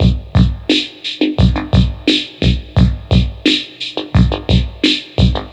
• 87 Bpm Drum Loop Sample B Key.wav
Free breakbeat - kick tuned to the B note. Loudest frequency: 1139Hz
87-bpm-drum-loop-sample-b-key-ikk.wav